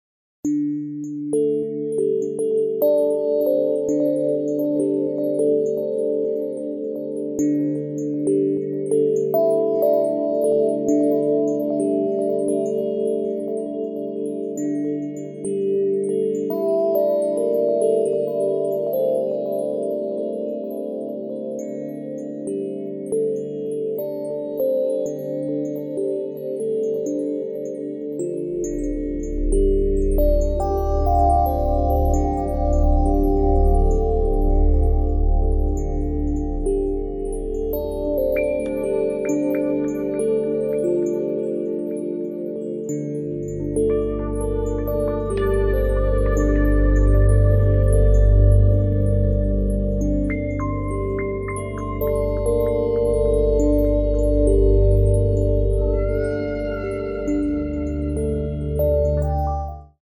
Музыка для мам и малышей